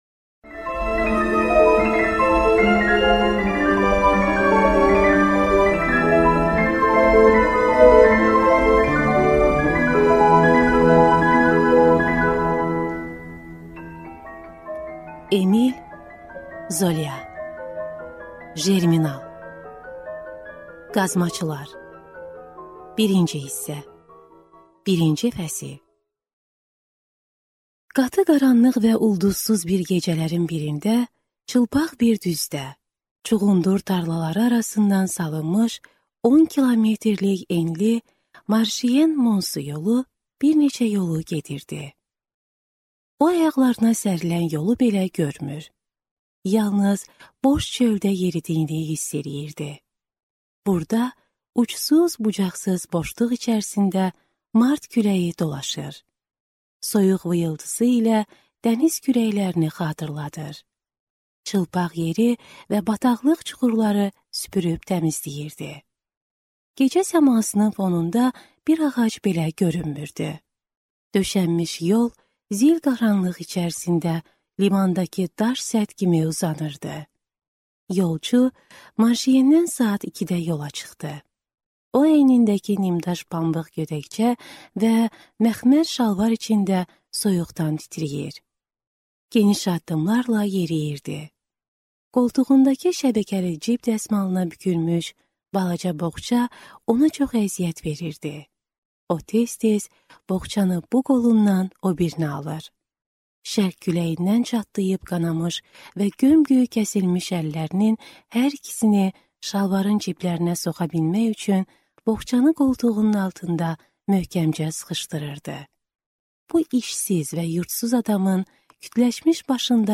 Аудиокнига Jerminal | Библиотека аудиокниг